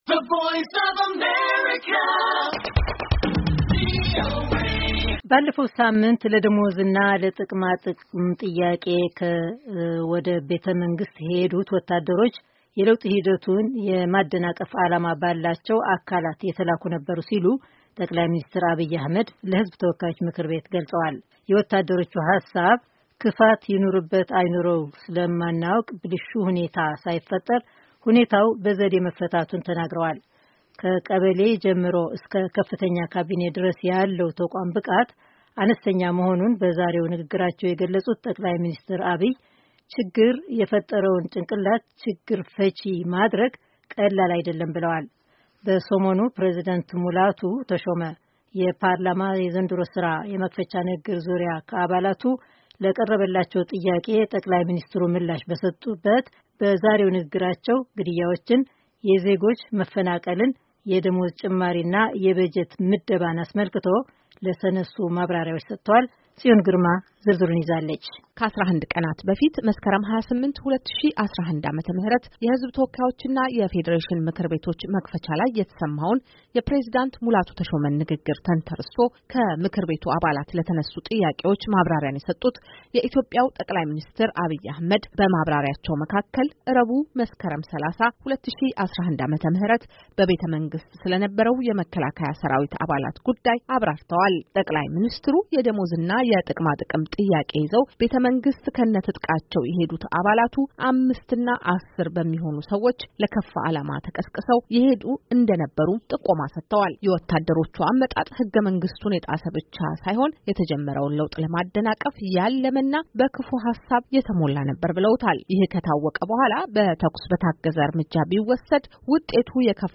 ጠቅላይ ሚኒስትር አብይ አሕመድ በፓርላማ ማብራሪያ እየሰጡ